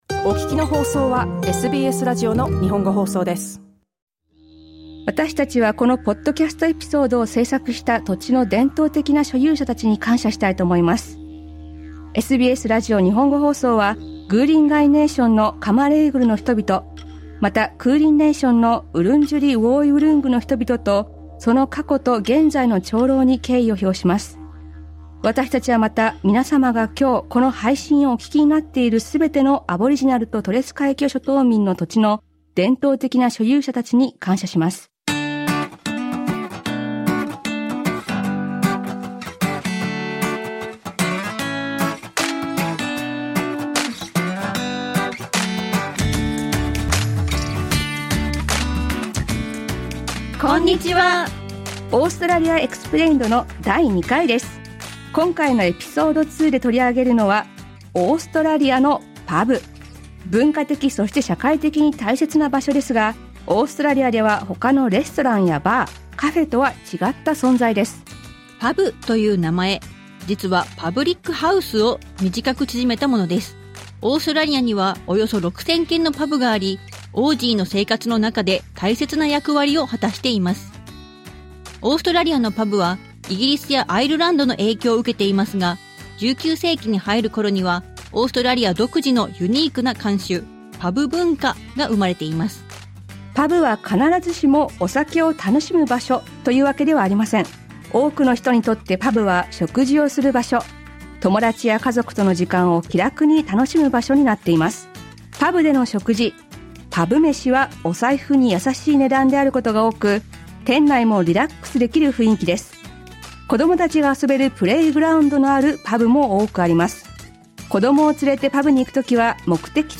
このエピソード２ではオーストラリアのパブについて、オーストラリアそして日本に住む人にお話を聞きました。